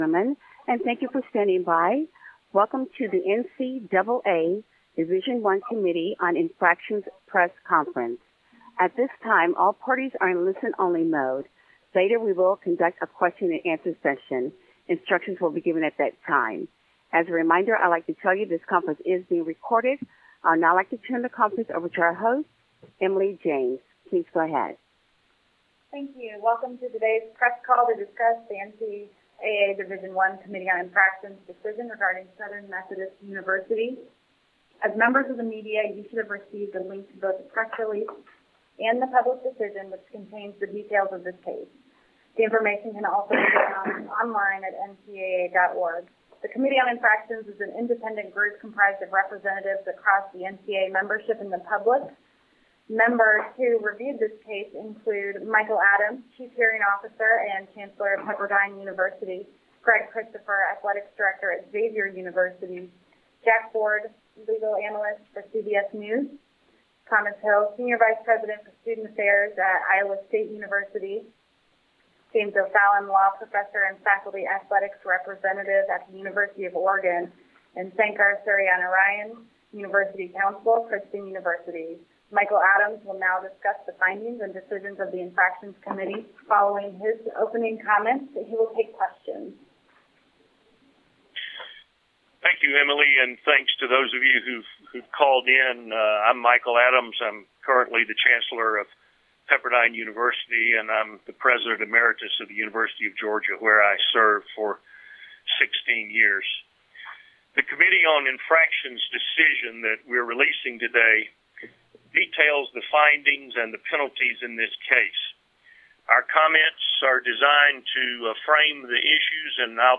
NCAA Division I Committee on Infractions Media Teleconference Concerning Southern Methodist University